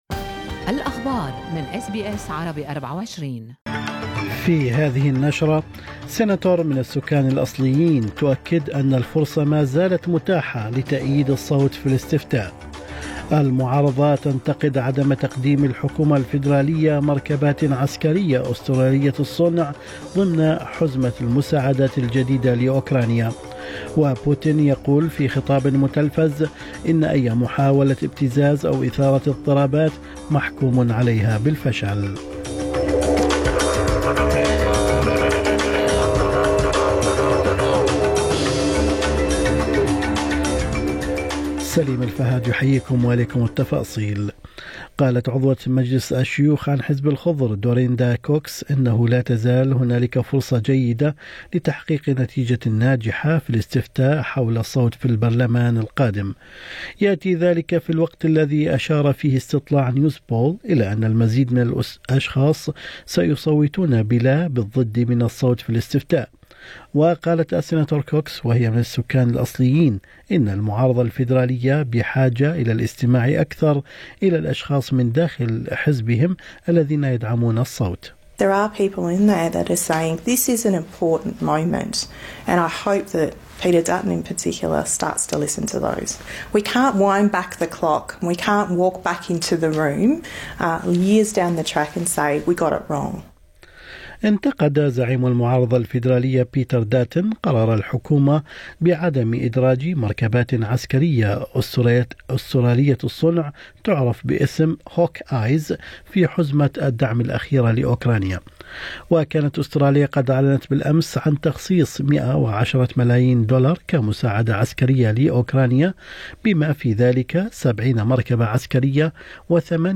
نشرة اخبار الصباح 27/6/2023